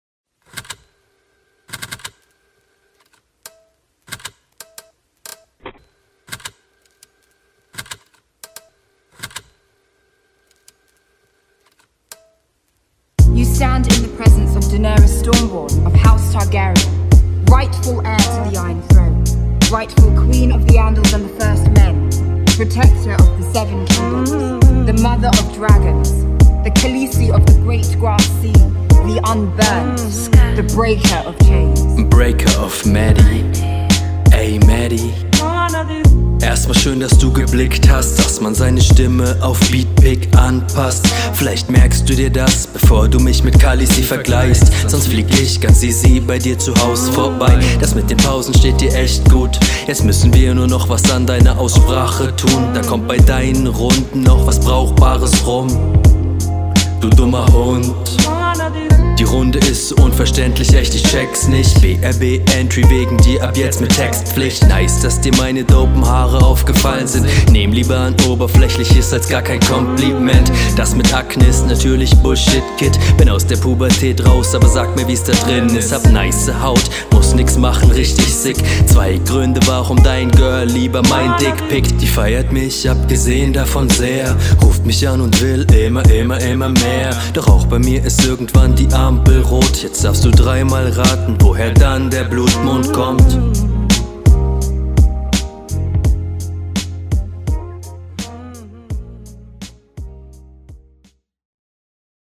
Man merkt, dass dir der Beat nicht ganz liegt. hier und da waren so paar …